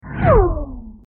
A Car Whizzing By 03
a_car_whizzing_by_03.mp3